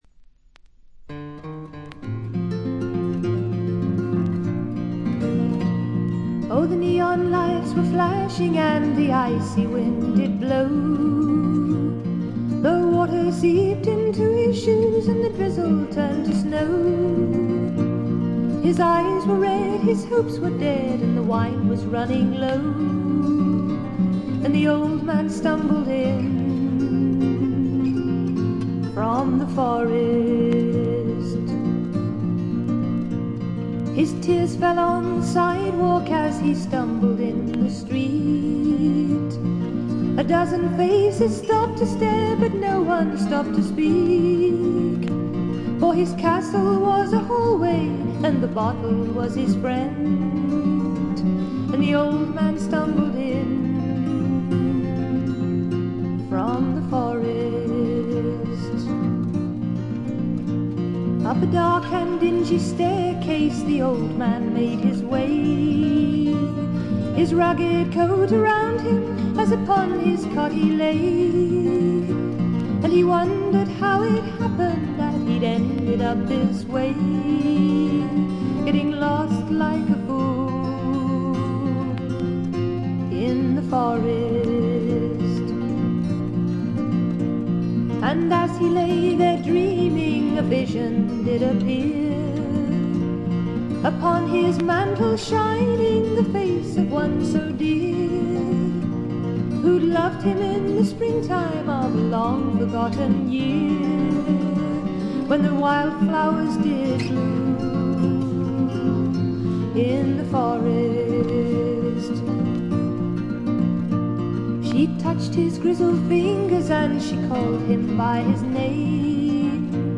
静音部でバックグラウンドノイズ、ところどころでチリプチ、散発的なプツ音少し。
試聴曲は現品からの取り込み音源です。